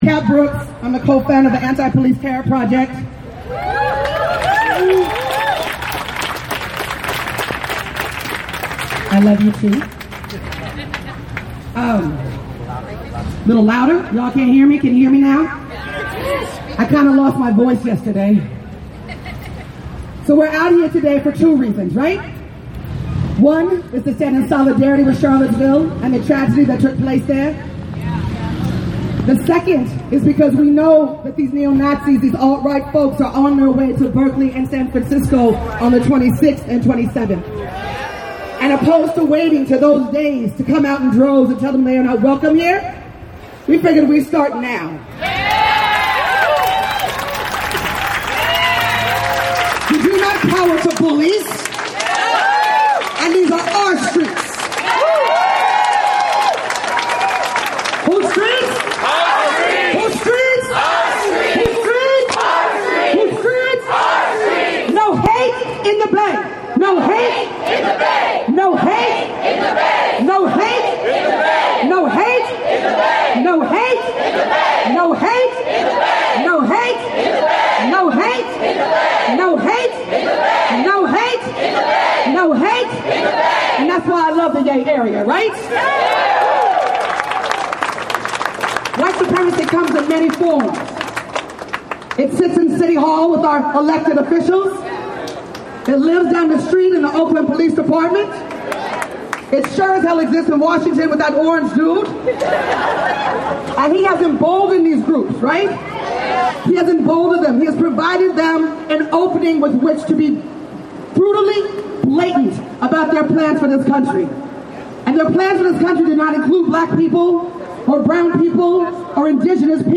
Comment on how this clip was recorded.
In Oakland, hundreds of protesters shut down I-580 that night. The following afternoon, a rally was held in Latham Square. Full audio of the rally below.